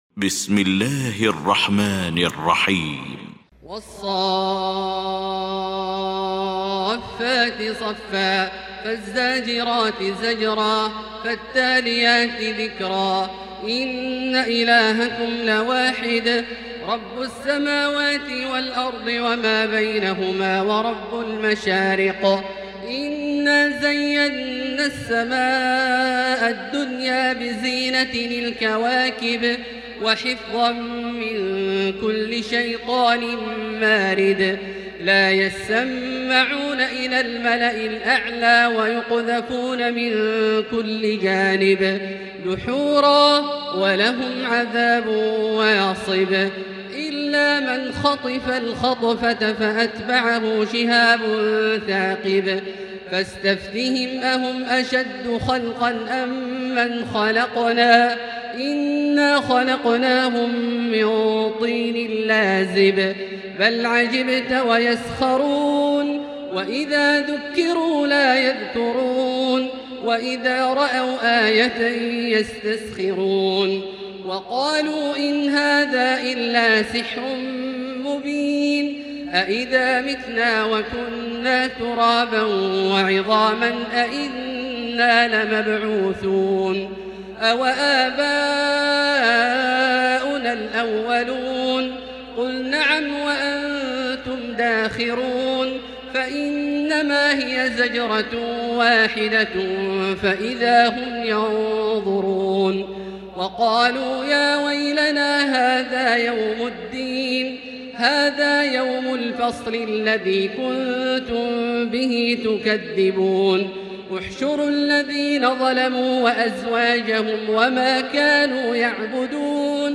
المكان: المسجد الحرام الشيخ: فضيلة الشيخ عبدالله الجهني فضيلة الشيخ عبدالله الجهني الصافات